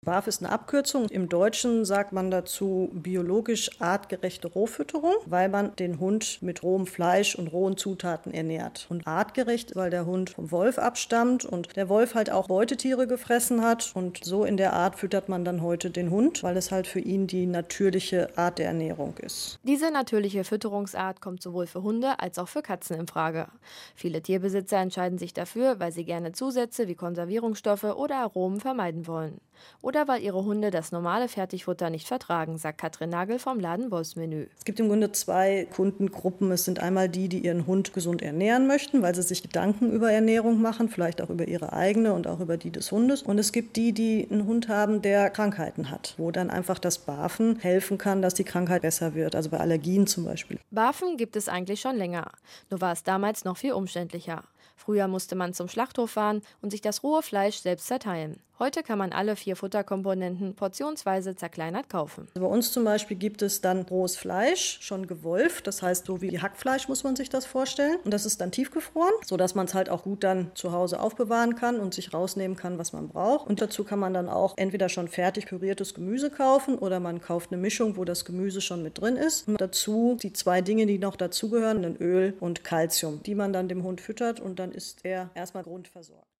Auszug Interview mit Radio Hagen aus 2015: